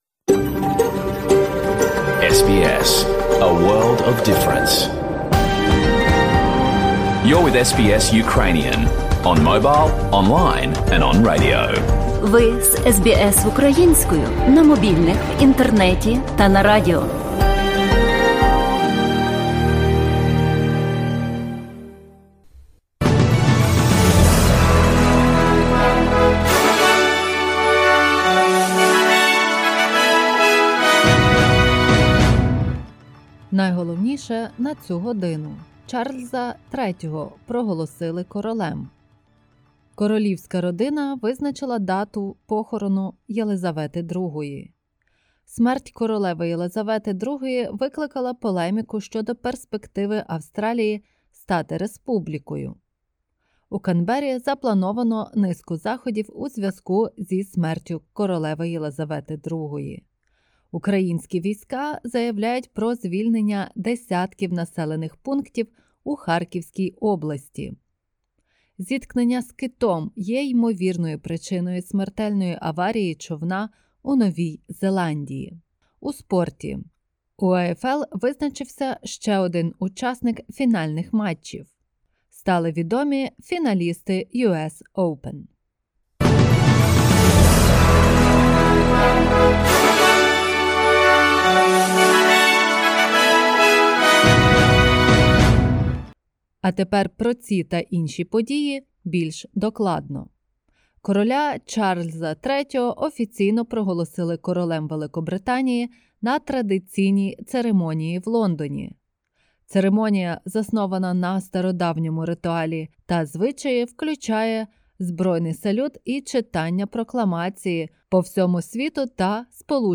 SBS News in Ukrainian – 11/09/2022